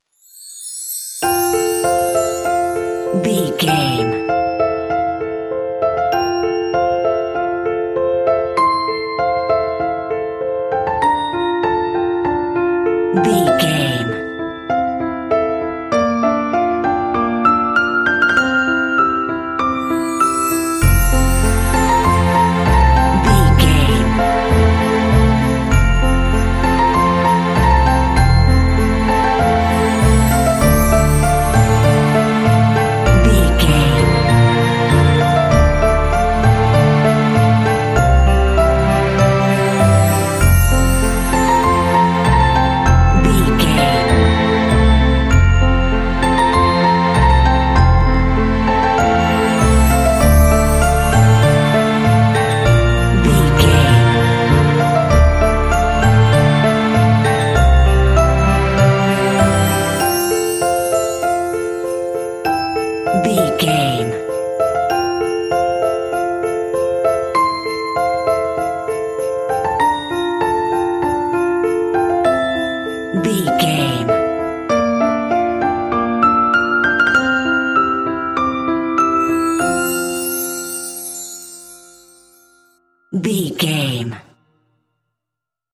In-crescendo
Uplifting
Ionian/Major
Fast
festive
hopeful
soft
dreamy
strings
percussion